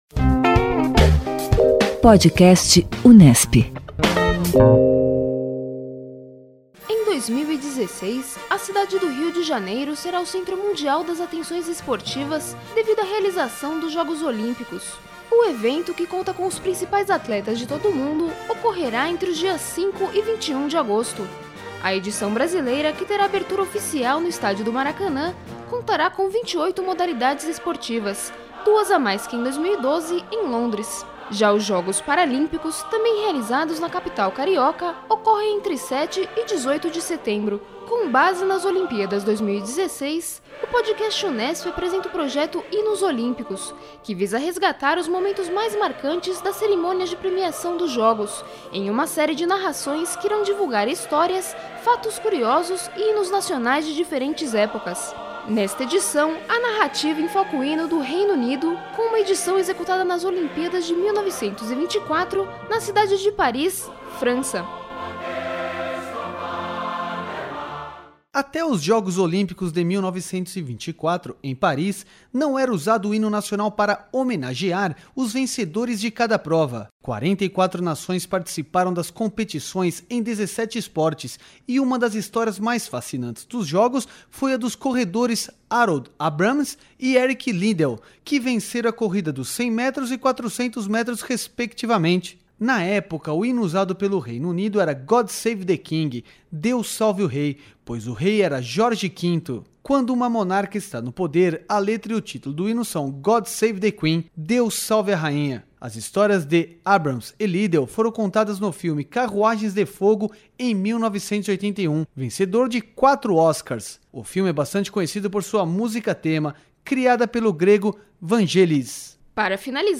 Nesta edição, o Podcast Unesp apresenta o hino do Reino Unido executado em Paris em 1924, quando a obra ainda homenageava o rei britânico com "God Save the King".